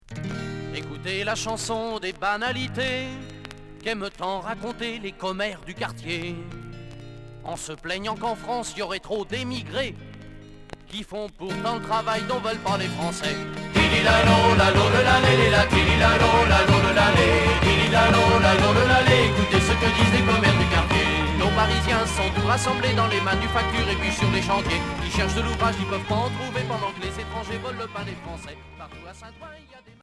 Folk celtique Quatrième 45t retour à l'accueil